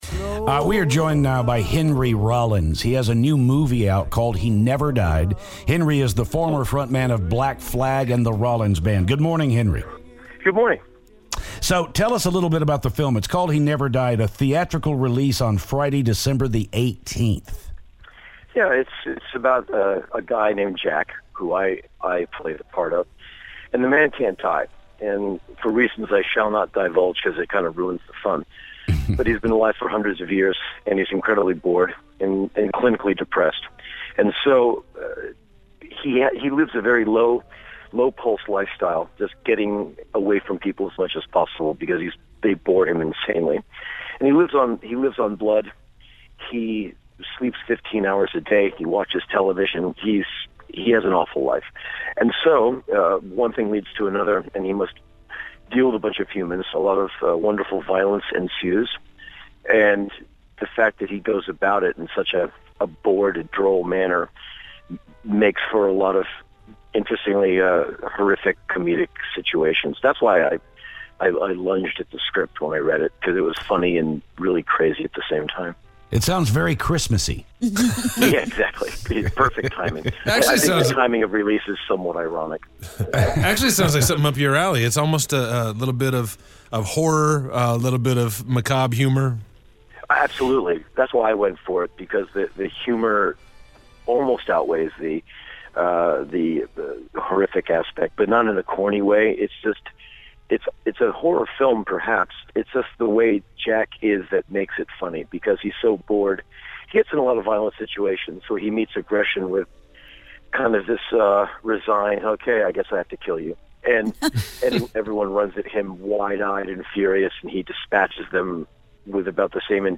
Actor/Musician Henry Rollins calls to talk about his new movie.